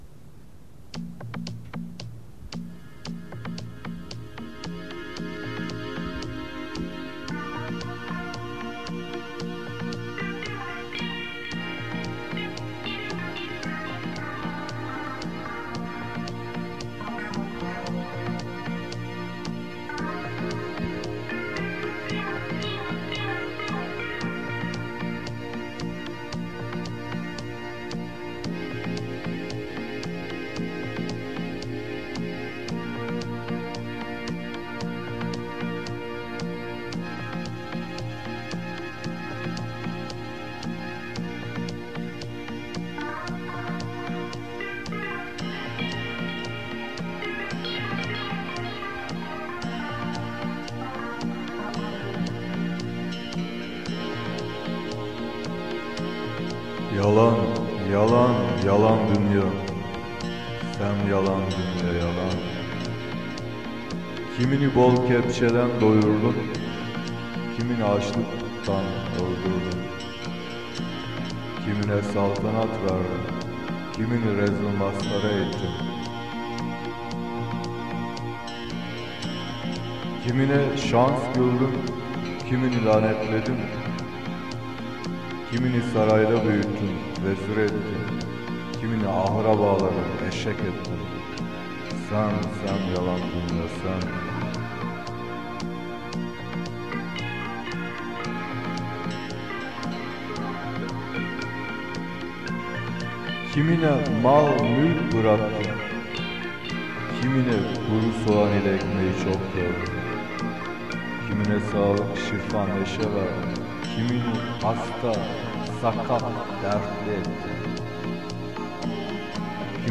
Hızlı ve yüksek sesli Pop-Rockt' tan (bağrı-çağrı).
Çalgı sesleri (Enstrümantal) genelde bilgisayar destekli
Hacimsel enstrümantal sesler arka plandan yansıtılarak